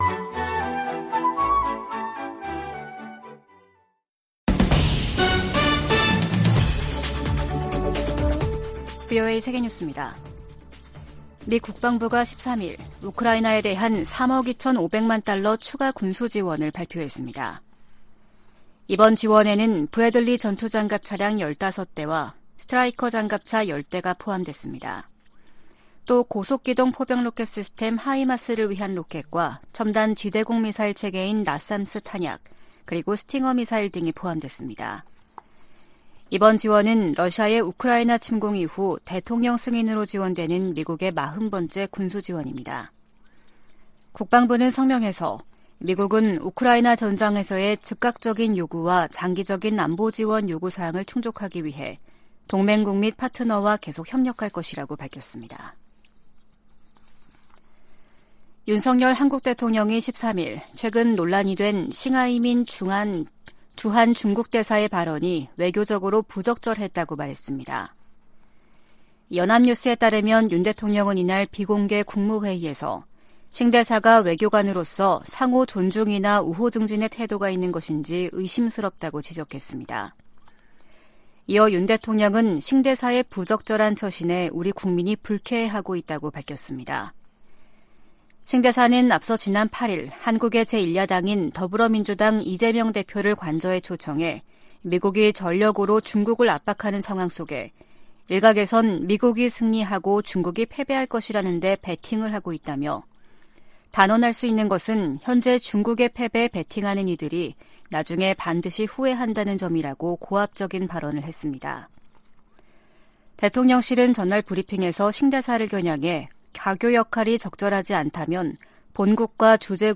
VOA 한국어 아침 뉴스 프로그램 '워싱턴 뉴스 광장' 2023년 6월 14일 방송입니다. 미국의 북핵 수석 대표는 워싱턴에서 한국의 북핵 수석대표와 회담한 후 북한의 추가 도발에 독자제재로 대응할 것이라는 입장을 밝혔습니다. 북한은 군사정찰위성 추가 발사 의지를 밝히면서도 발사 시한을 미리 공개하지 않겠다는 입장을 보이고 있습니다.